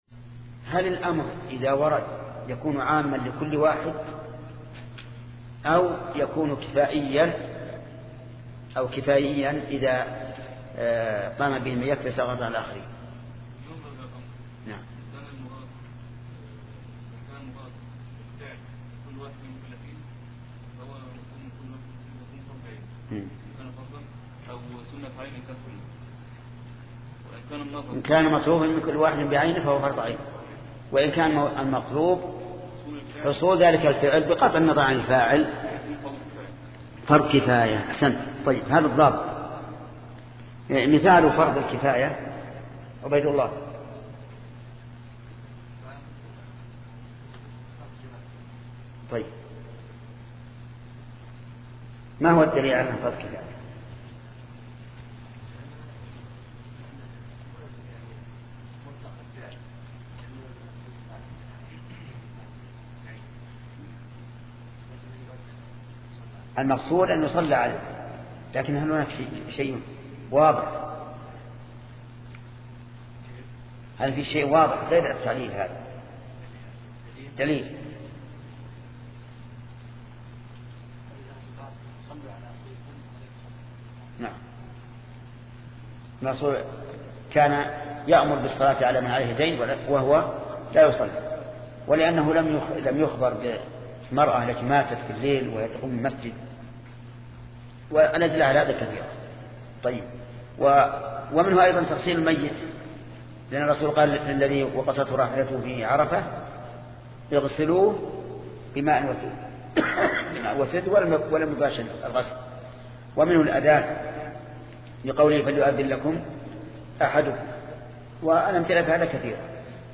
شبكة المعرفة الإسلامية | الدروس | شرح المنظومة في أصول الفقه 7 |محمد بن صالح العثيمين